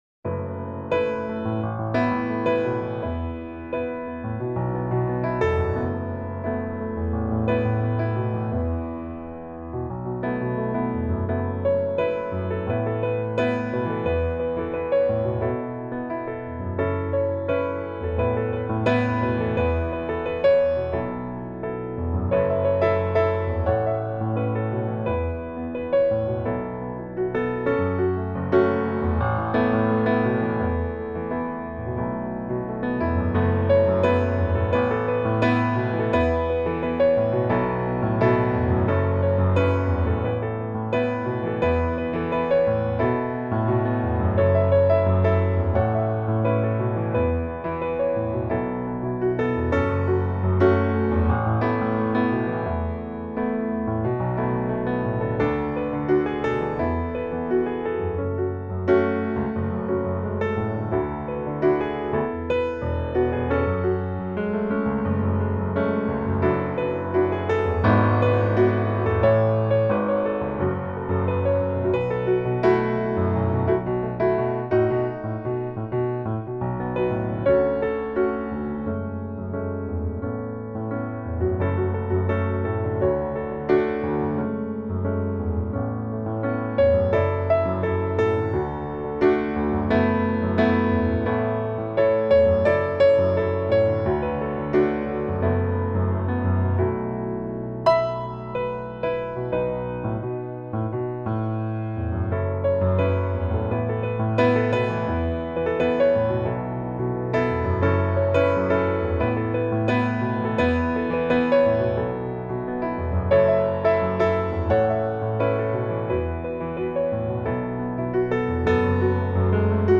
Piano Demos